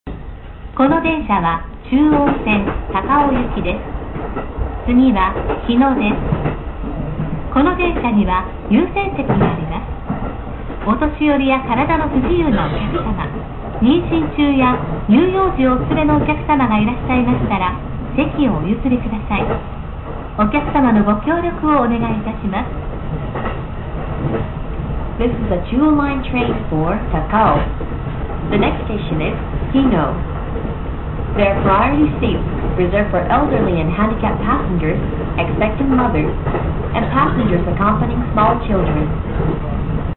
自動放送|立川〜日野間|
tachikawa-hino.mp3